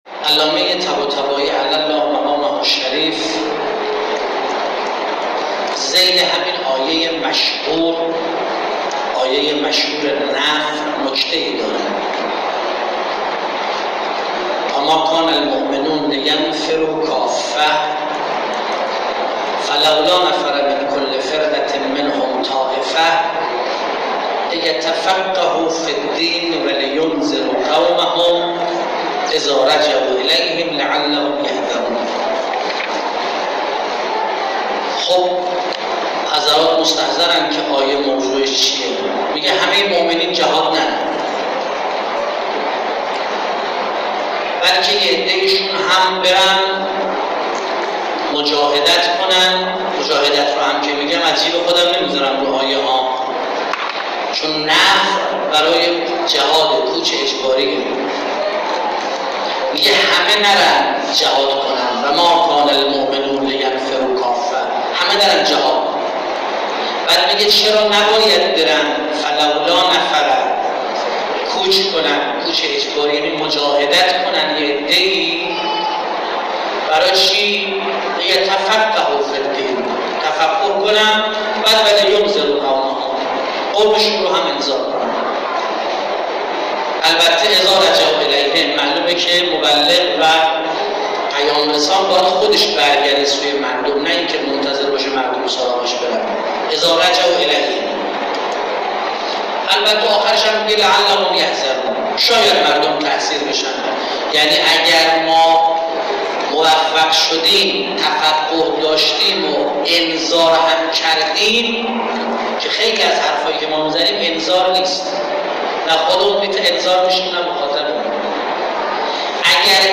در اولین نشست تخصصی فرهنگی و بصیرتی دانش آموختگان حوزه های علمیه خواهران استان تهران که درحسینیه فاطمه الزهرا(س) برگزار شد